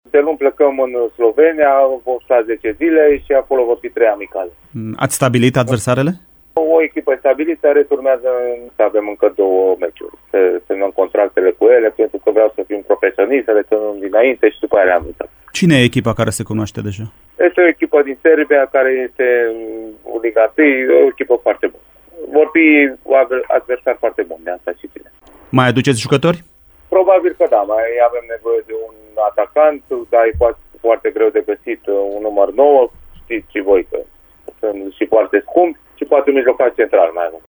Într-un interviu la Radio Timișoara